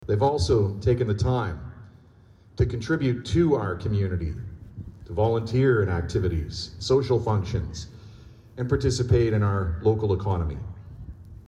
Minister of Energy and Bay of Quinte riding MPP Todd Smith said past and present members of the RCAF and 8 Wing have not served only the country, but our community.